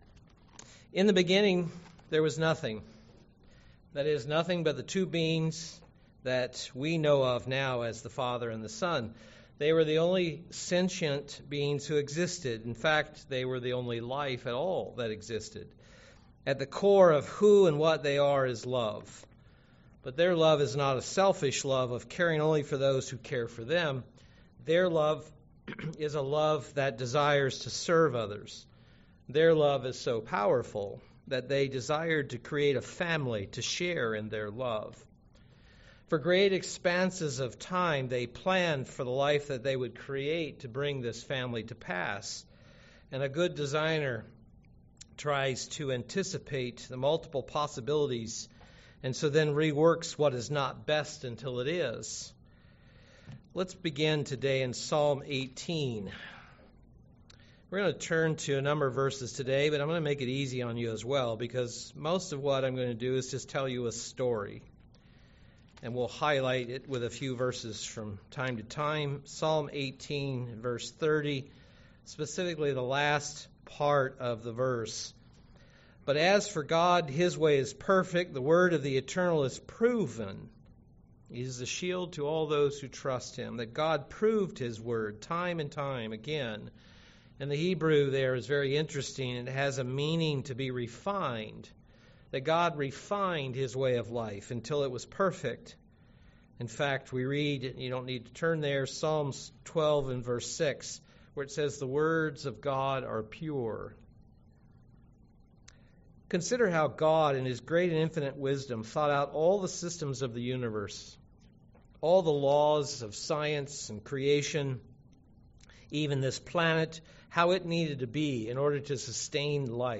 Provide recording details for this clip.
Given in Milwaukee, WI